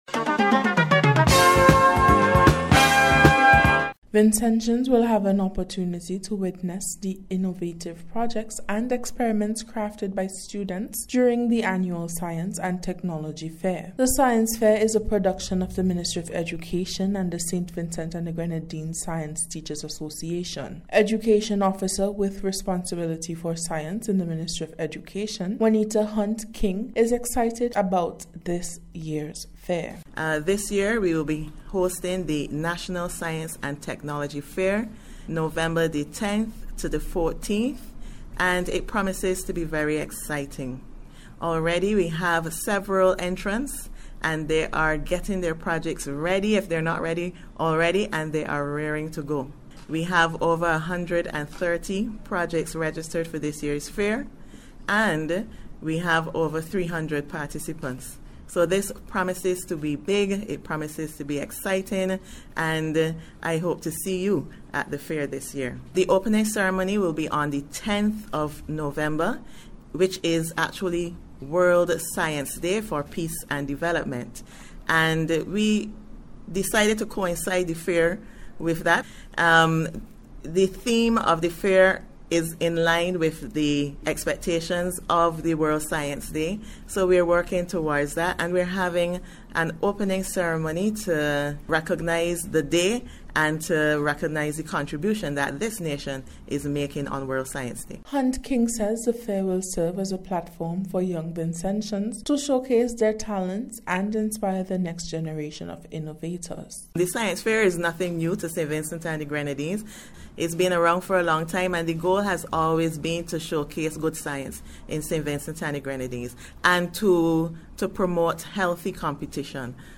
SCIENCE-FAIR-REPORT.mp3